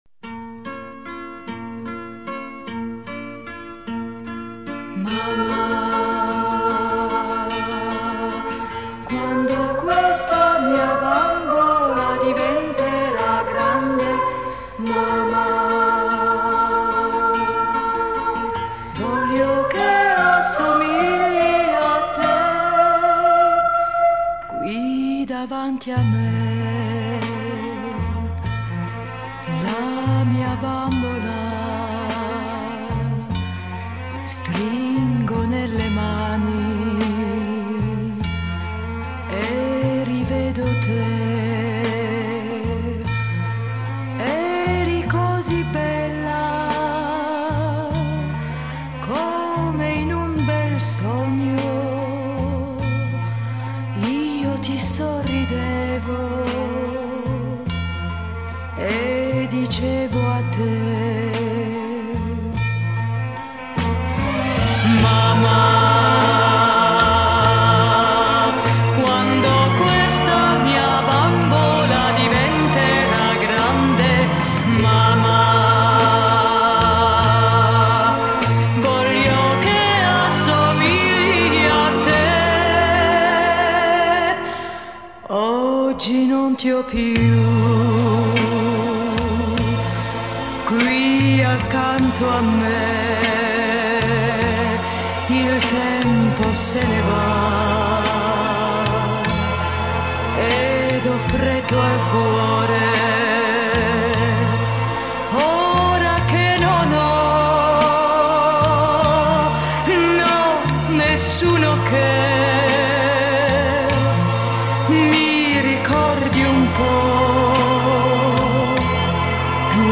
До сей поры вообще не слышал вокальной версии этого шедевра.